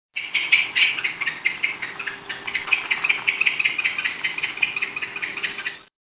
barn owl
Tyto alba
This was recorded at Elkhorn Slough in Central California, where a pair had taken up residence in (you guessed it!) a barn!